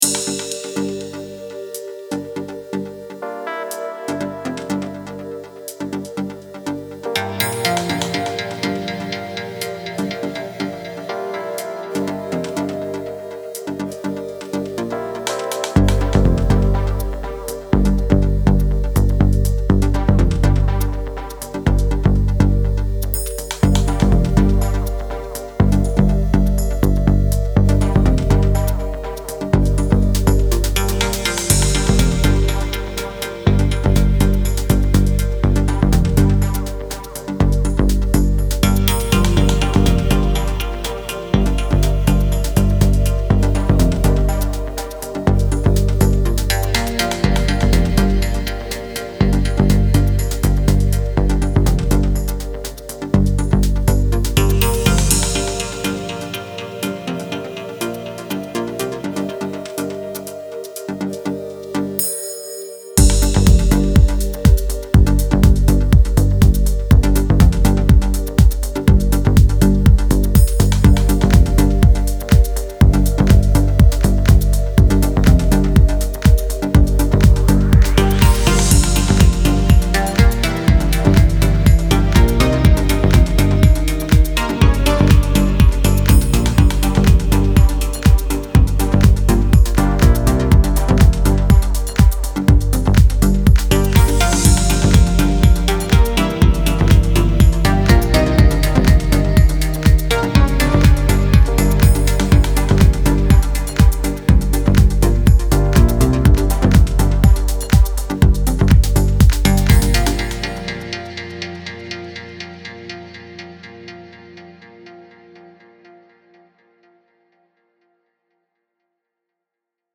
Deep house / Chillout - сведение, частотный баланс